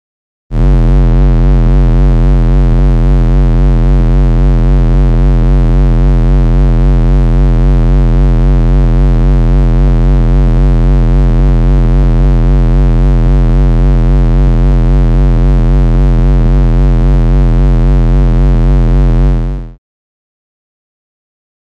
Low Frequency Modulation 1; Extremely Low Frequency Warble Tone; Regular Pulsation, Close Perspective.